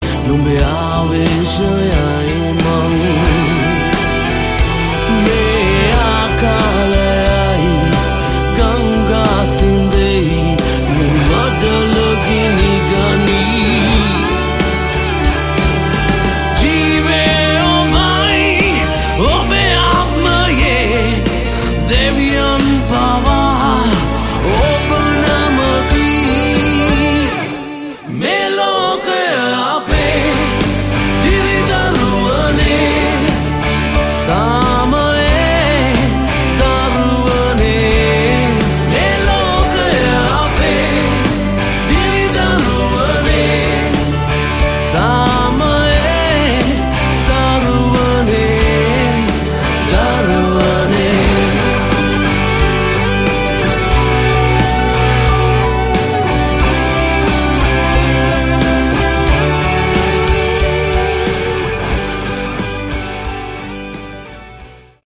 Category: Melodic Rock
vocals
guitar
bass
drums